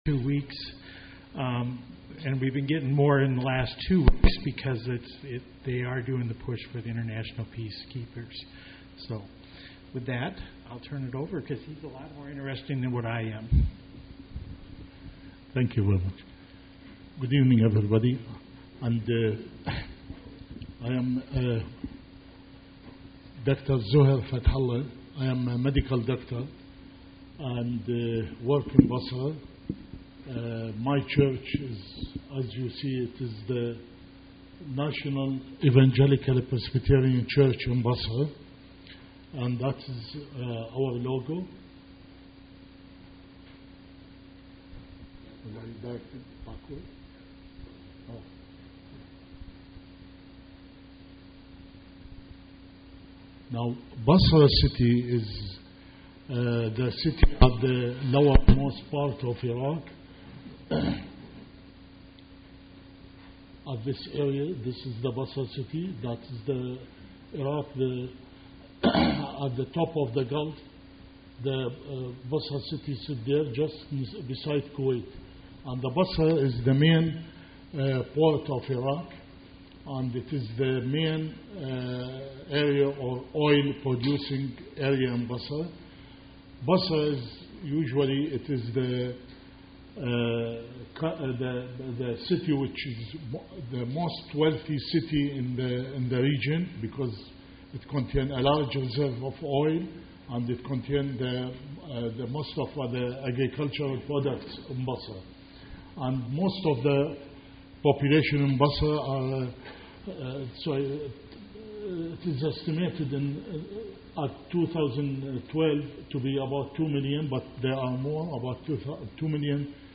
Mission Speaker provides information on his Middle East ministry